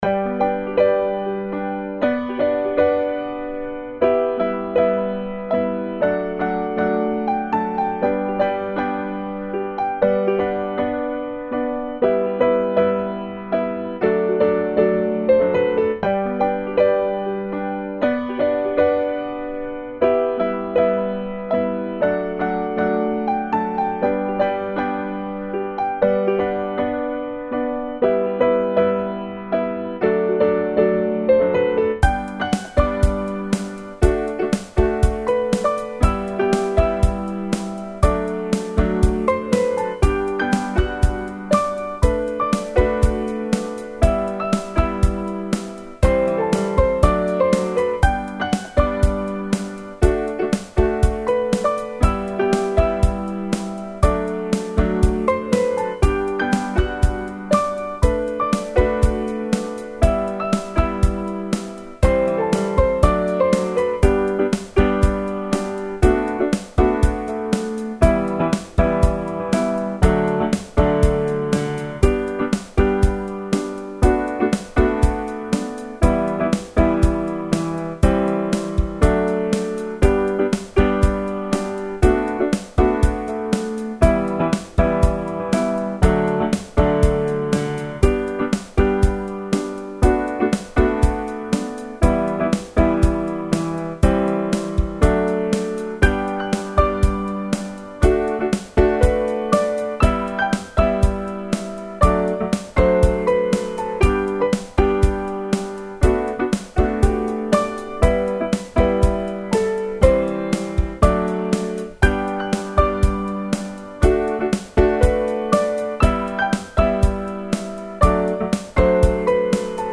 ポップロング明るい穏やか
BGM